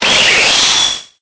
Cri_0880_EB.ogg